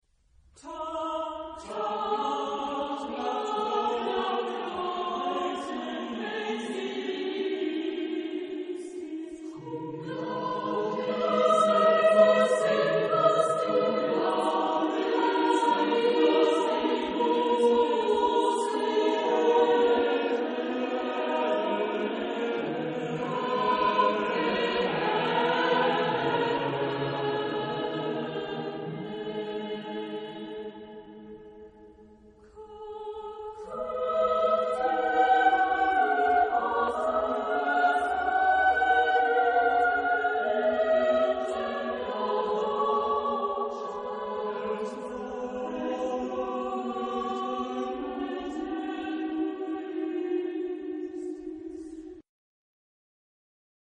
Género/Estilo/Forma: Motete ; Sagrado ; Renacimiento ; Barroco
Tipo de formación coral: SATB  (4 voces Coro mixto )